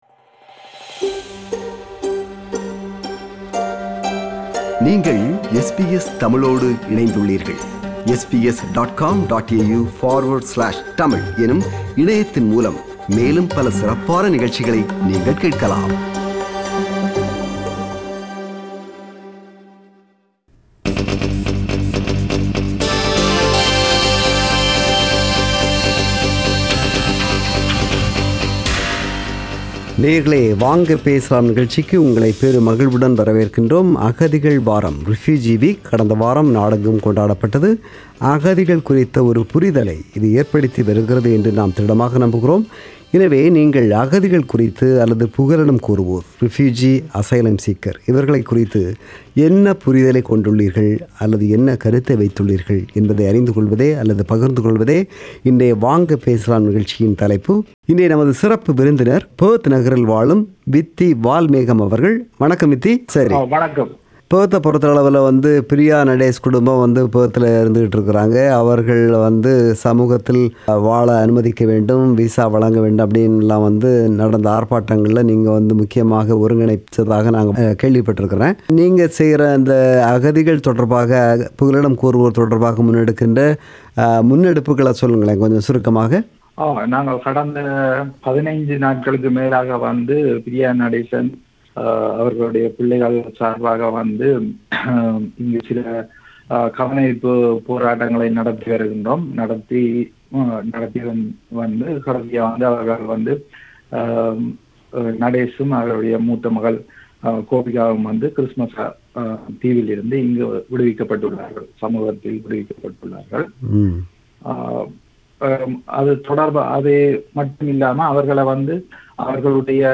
Talkback on Refugee Week
Refuge Week is an important reminder that we need to come together to recognise our common humanity and build an inclusive society with refugees and asylum-seekers. This is the compilation of views shared by our listeners today (27 June 2021) in “Vanga Pesalam” program.